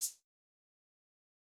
drop_cancel.ogg